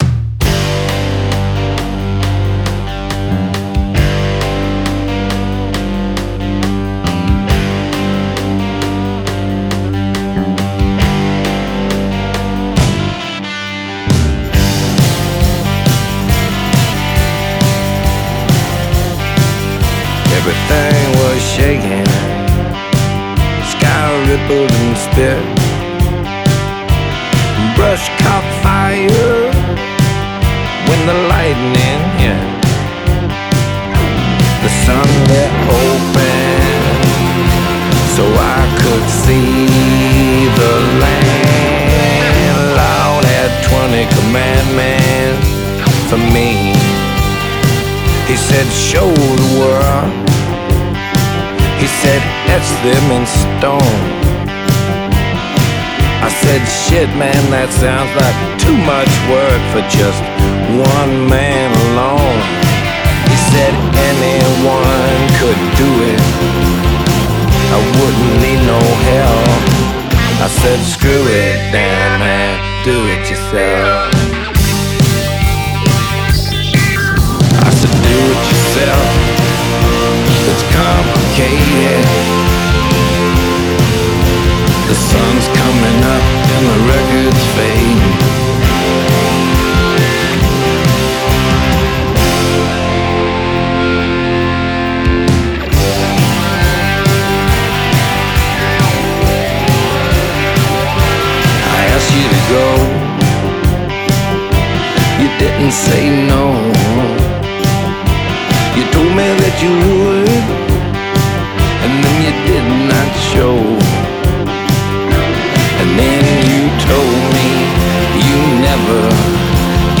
Genre: Folk Rock, Blues, Americana